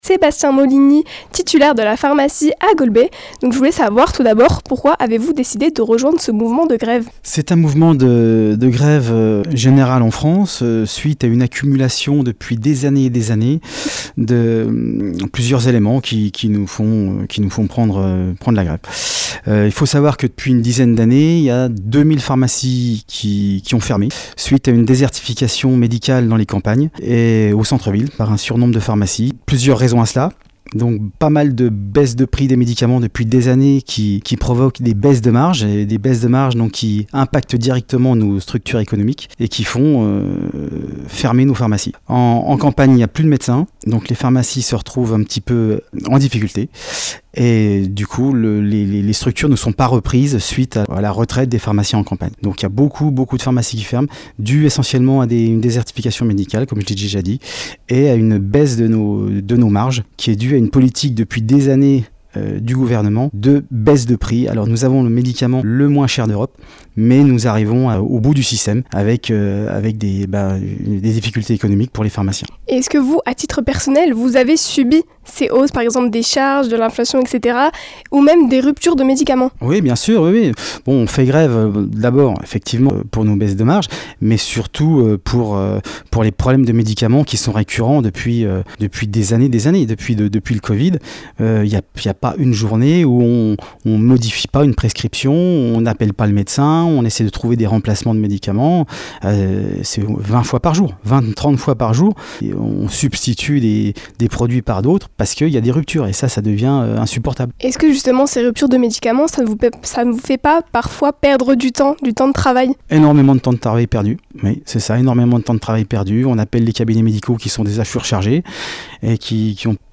Nous en parlons avec